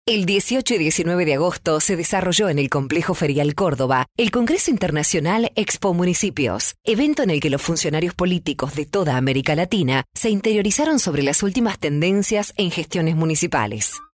Female Professional Voice Over Talent | VoicesNow Voiceover Actors
Free voice over demos.